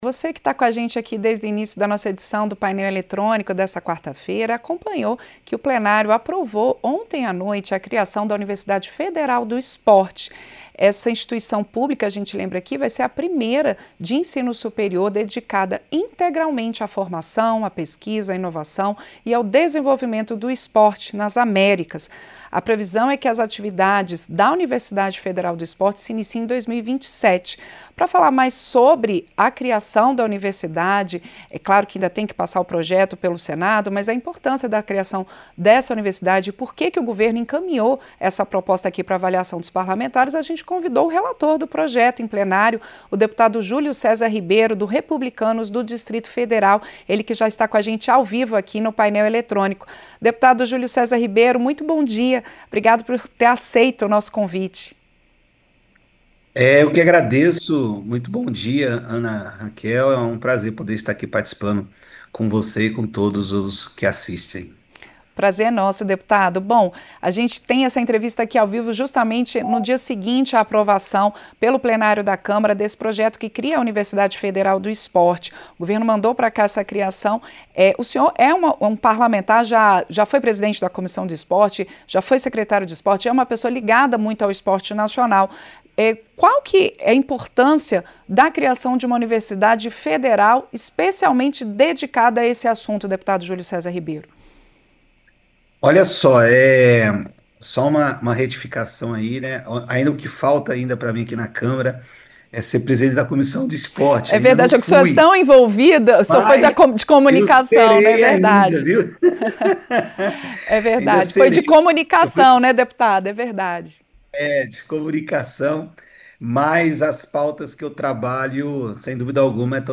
Entrevista - Dep. Júlio Cesar Ribeiro (Rep-DF)